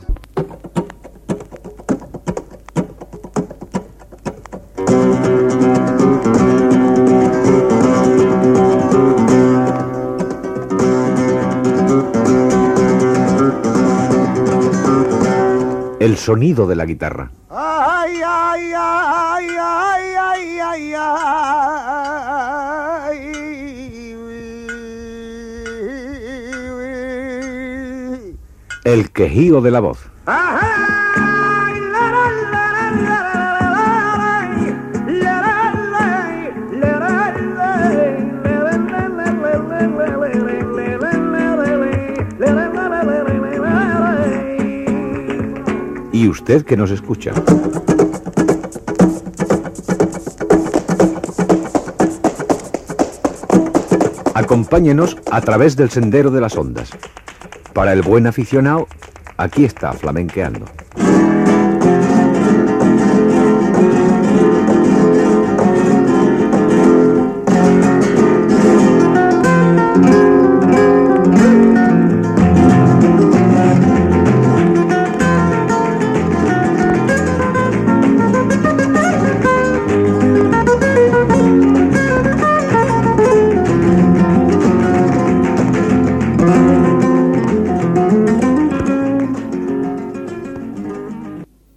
Careta del programa
Musical